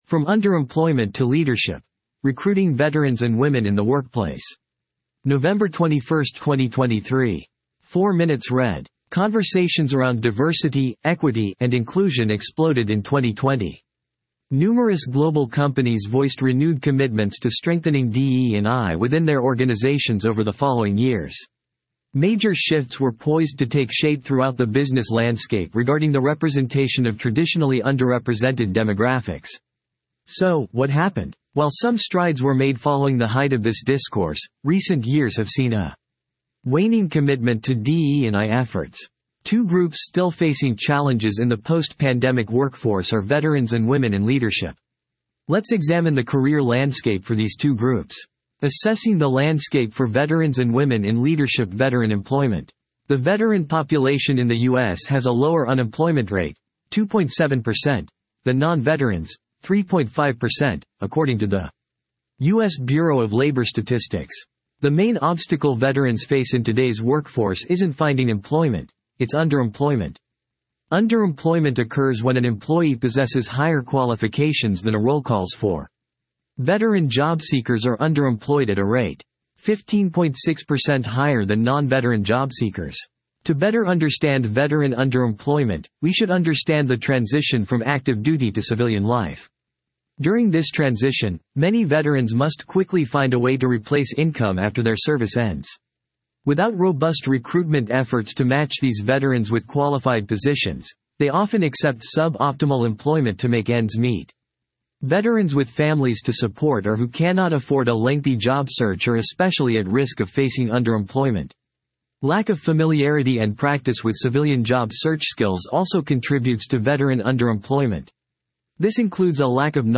You can use this audio player to convert website page content into human-like speech. 11:11 00:00 / 14:00 1.0X 2.0X 1.75X 1.5X 1.25X 1.0X 0.75X 0.5X Conversations around Diversity, Equity, and Inclusion exploded in 2020.